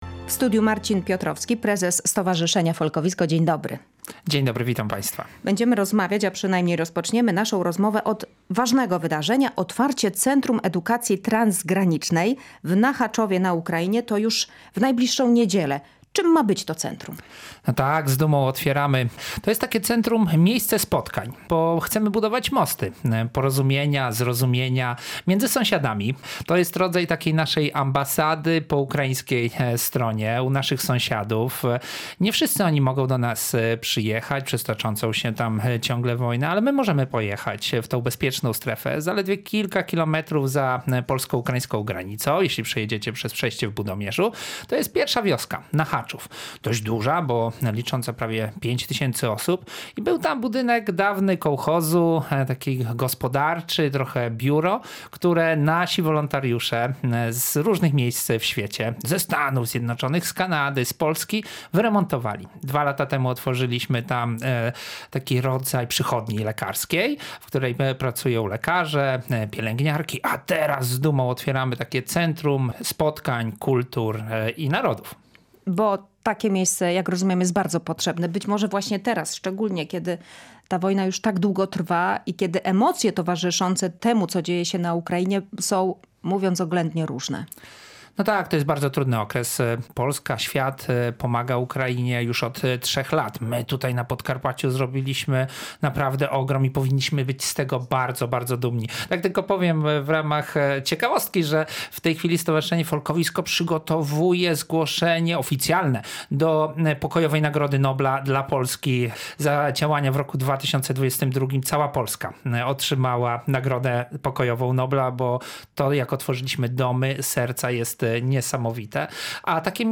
W Tu i Teraz rozmawialiśmy o nowej inicjatywie Stowarzyszenia Folkowisko i Fundacji Humanitarnej Folkowisko, które wraz z parterami w niedzielę